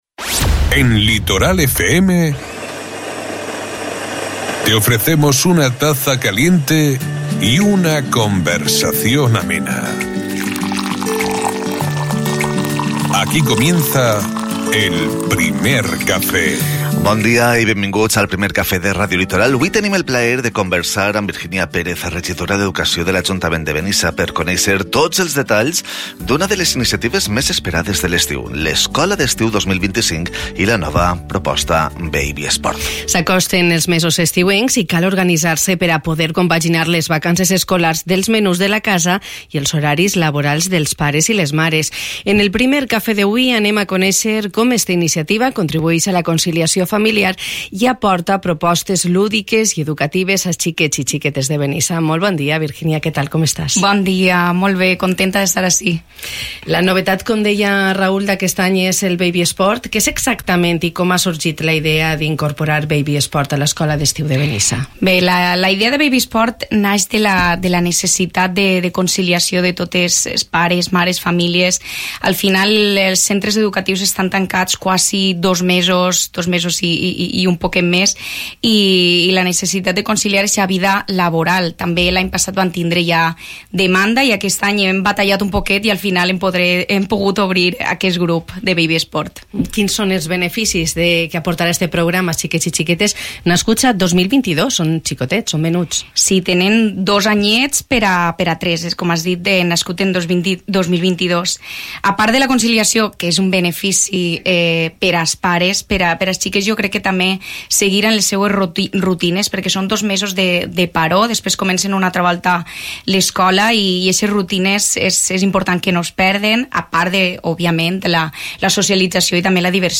Esta mañana hemos conversado con la concejala de Educación de Benissa, Virginia Pérez, sobre l’Escola d’Estiu 2025.